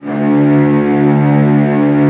CELLOS EN2-R.wav